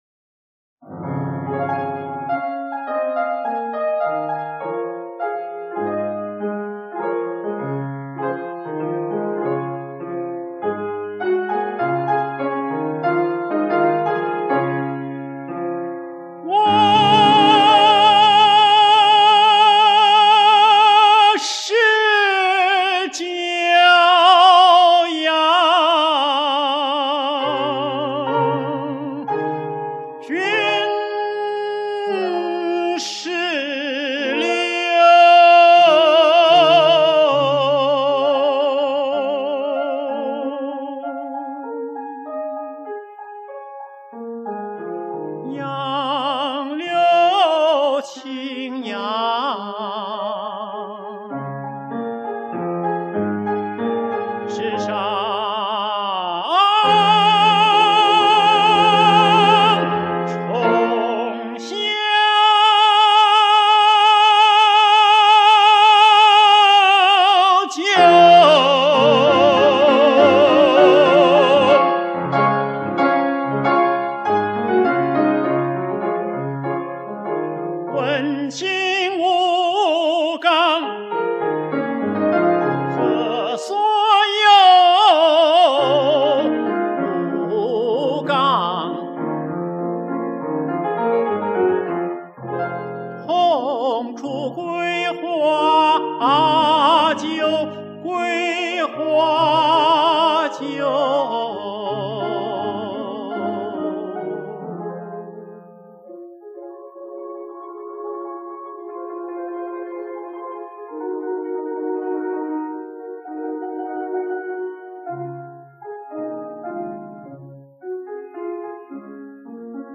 1962年北京录音
他的演唱含蓄内在、细腻深情，音色甜美，吐字清晰，特别是在高声区的弱音控制和延长分外动人。